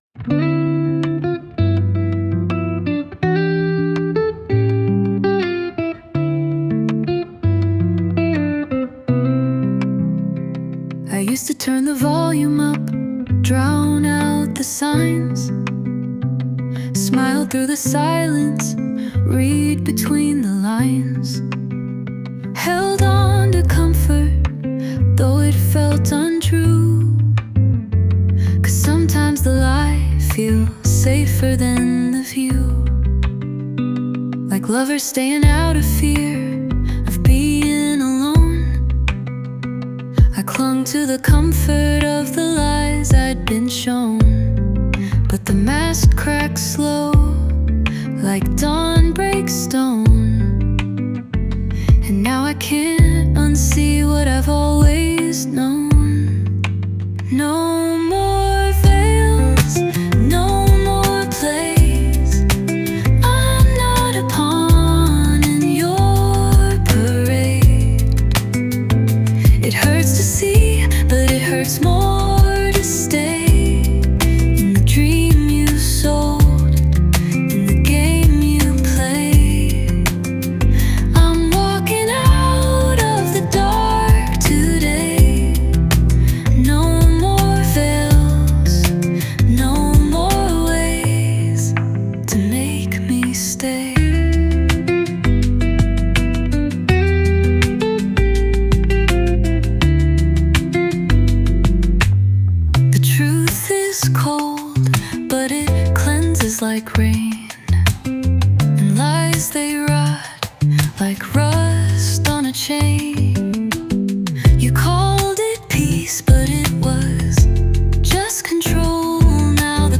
Latin, Pop